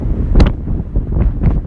wind sounds » wind12
描述：wind windy storm
标签： wind storm windy
声道立体声